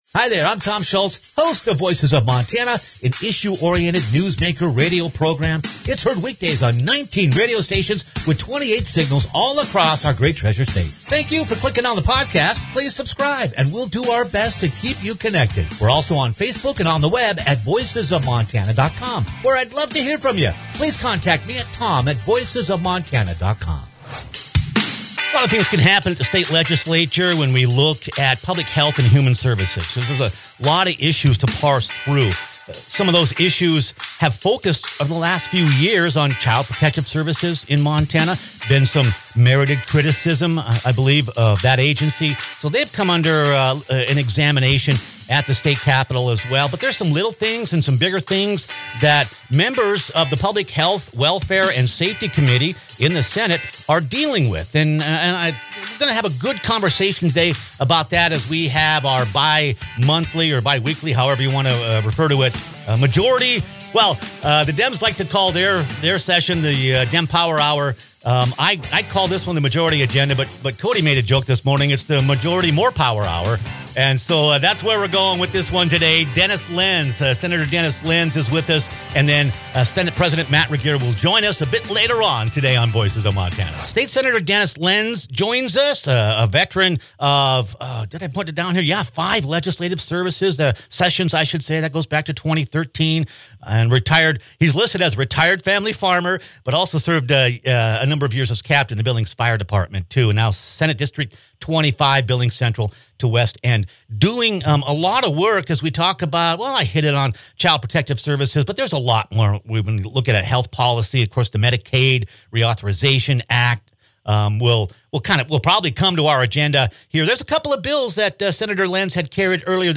GOP Senate Majority Whip Dennis Lenz, and Senate President Matt Regier join the program for their biweekly connection. Senator Lenz heads the Public Health, Welfare and Safety Committee and addresses a handful of the health and safety issues before the legislature.